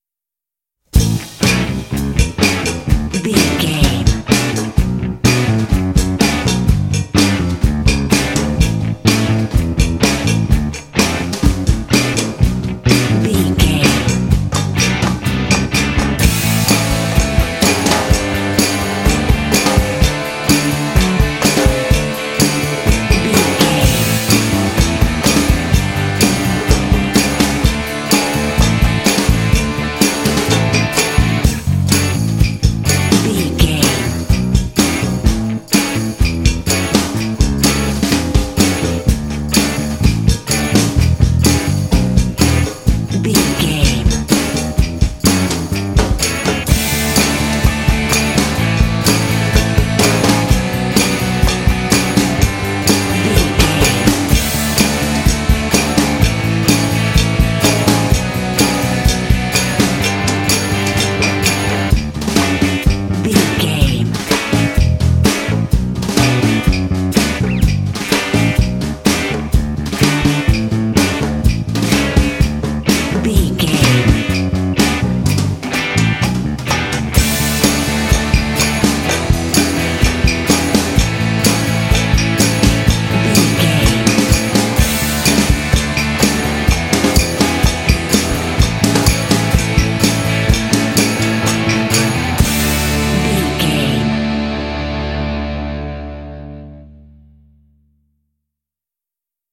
Ionian/Major
D
driving
cheerful/happy
lively
bass guitar
drums
electric guitar
percussion
rock
classic rock